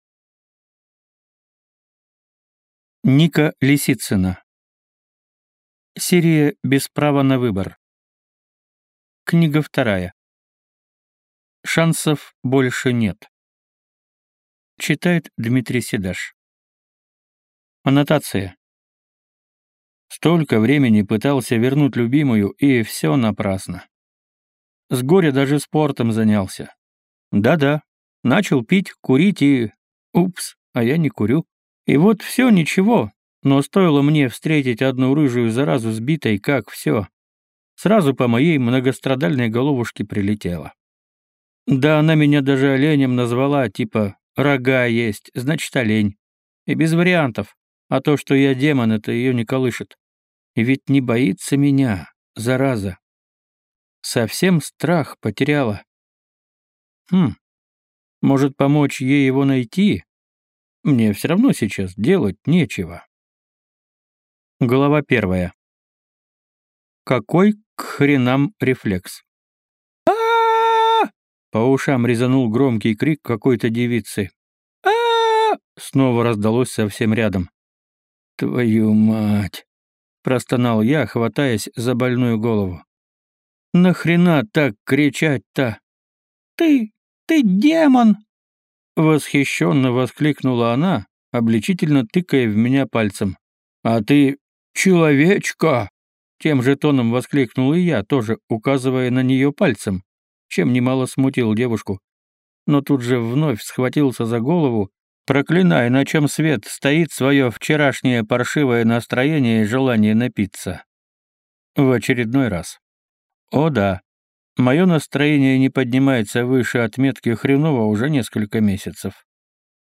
Аудиокнига Шансов больше нет. Без права на выбор 2 | Библиотека аудиокниг
Прослушать и бесплатно скачать фрагмент аудиокниги